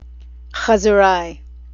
Chazzerai: (khaz-zer-rye) literally, pig slop. Any kind of garbage, whether it's junk food, shoddy merchandise or stuff of little or no value.